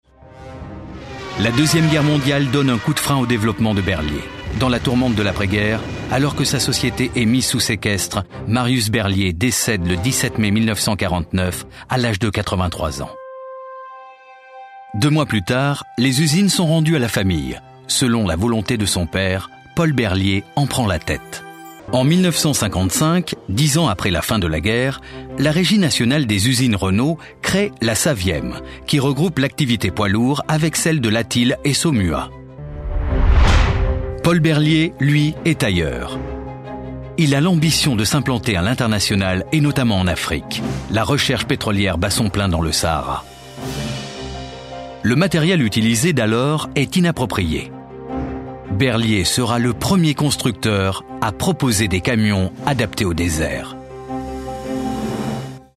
BERLIET (documentaire, corporate)